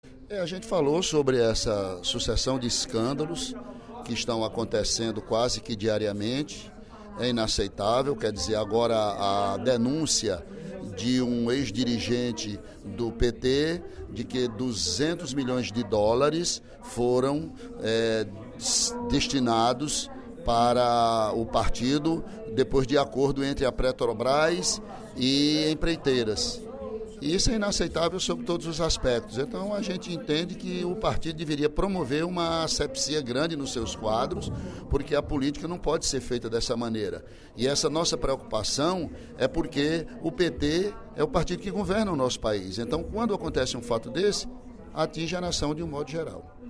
O deputado Ely Aguiar (PSDC) disse, nesta sexta-feira (06/02), durante o primeiro expediente da Assembleia Legislativa, que os “petistas ilibados” estão envergonhados com as denúncias de corrupção que proliferam no País, a partir das apurações da operação Lava Jato, da Polícia Federal.